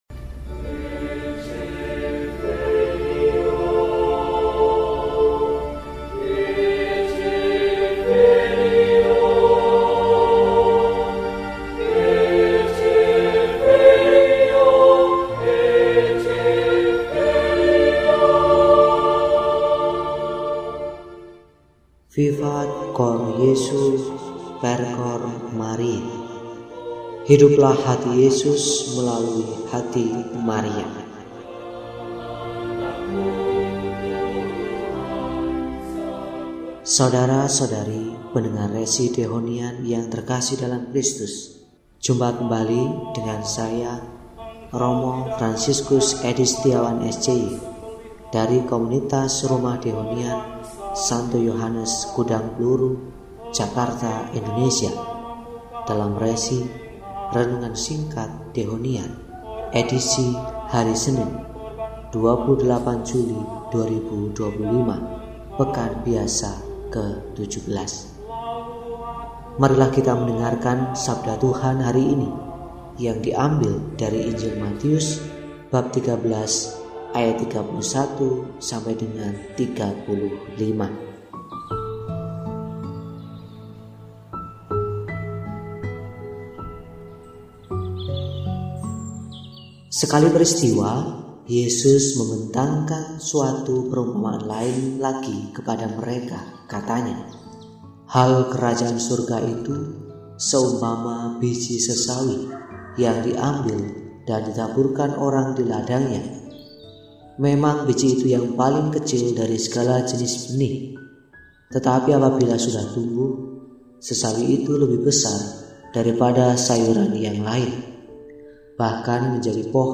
Senin, 28 Juli 2025 – Hari Biasa Pekan XVII – RESI (Renungan Singkat) DEHONIAN